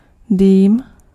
Ääntäminen
Synonyymit (Québecin ranska) boucane Ääntäminen France: IPA: [fyme] Haettu sana löytyi näillä lähdekielillä: ranska Käännös Ääninäyte Substantiivit 1. dým {m} 2. kouř {m} Suku: f .